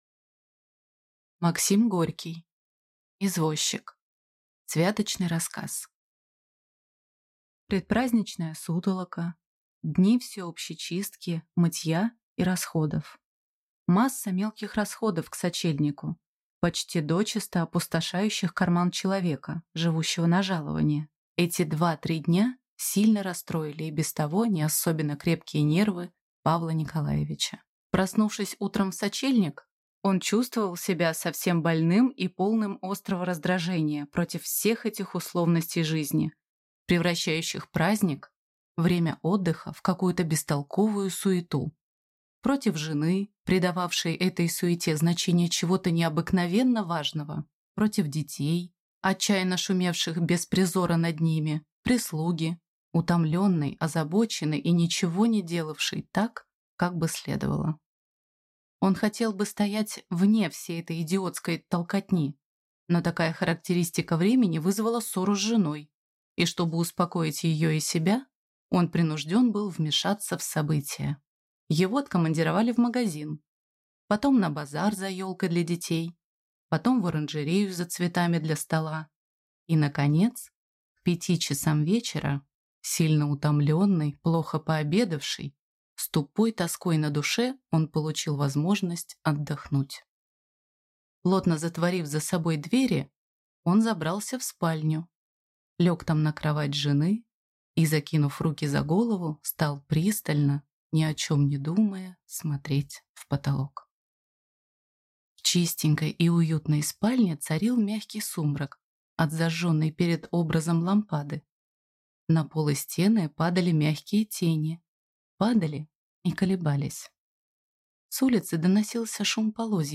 Аудиокнига Извозчик | Библиотека аудиокниг